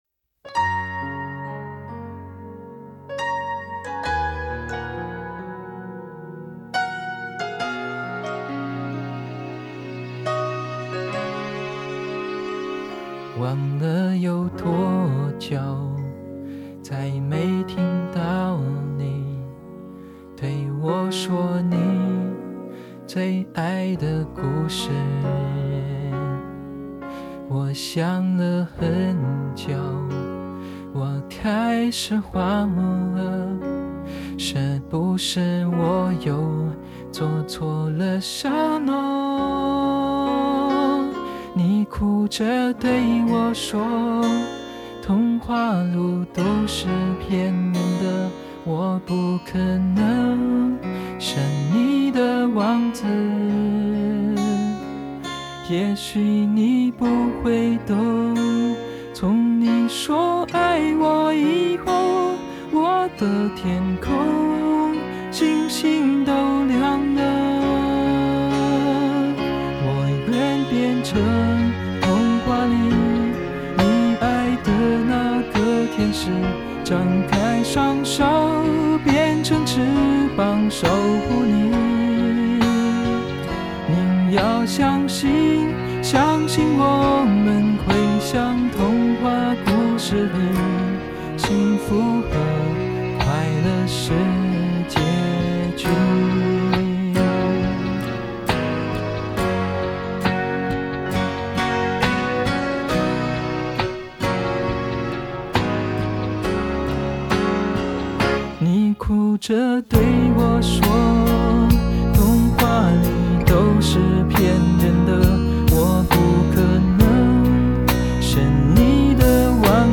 # 适合文本朗读模型# 男声# 旁白/播客声音模型